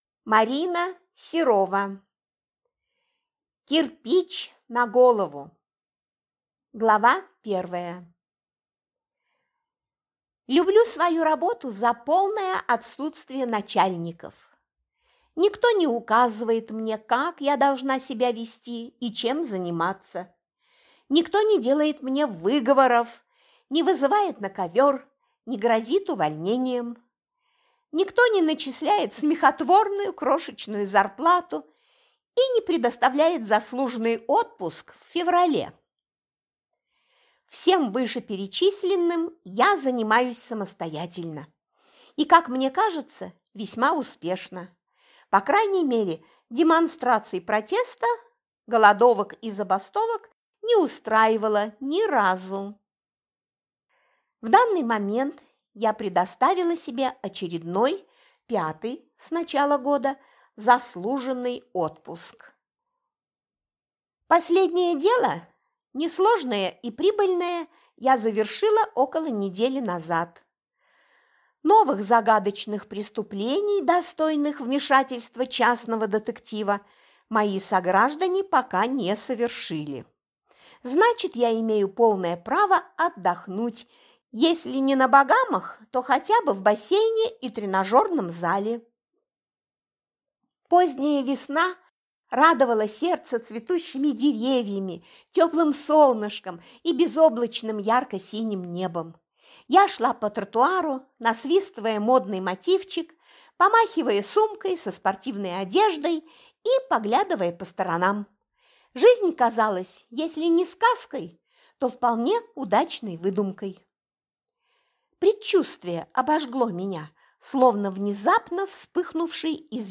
Аудиокнига Кирпич на голову | Библиотека аудиокниг
Прослушать и бесплатно скачать фрагмент аудиокниги